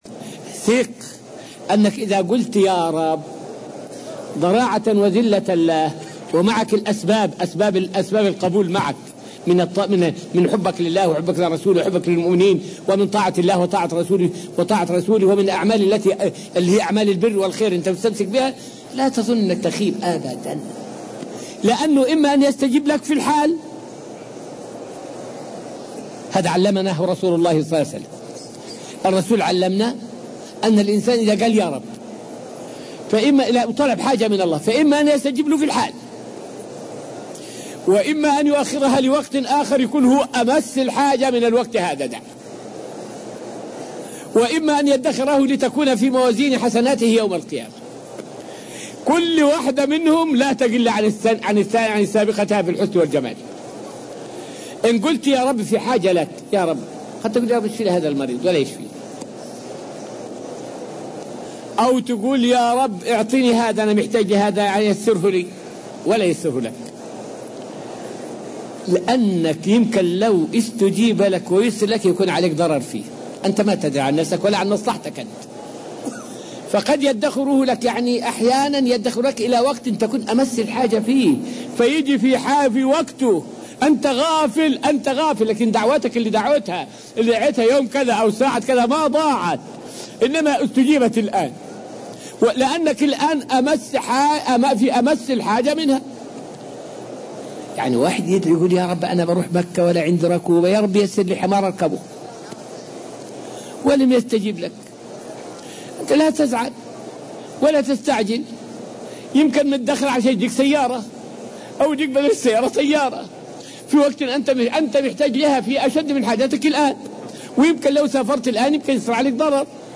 فائدة من الدرس الخامس والعشرون من دروس تفسير سورة البقرة والتي ألقيت في المسجد النبوي الشريف حول الاستجابة مع الدعاء.